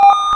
get_coin.ogg